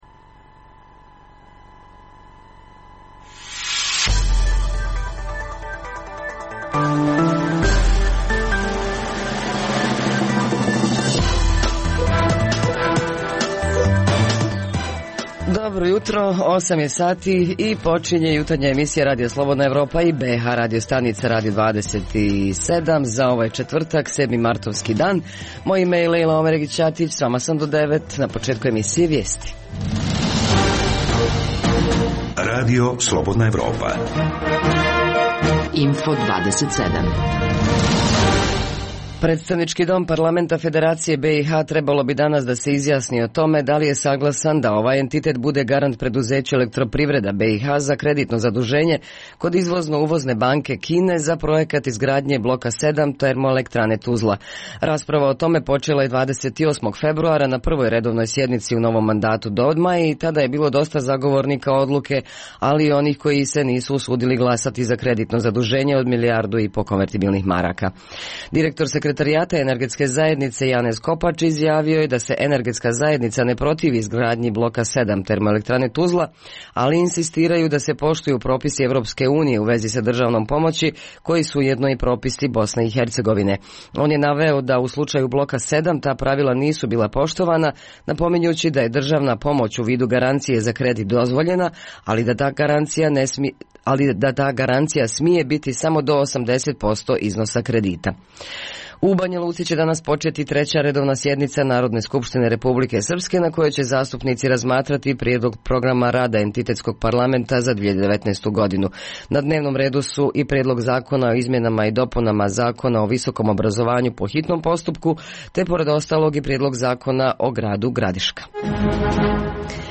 O tome priče imaju naši dopisnici iz Mostara, Doboja, Banja Luke i Travnika.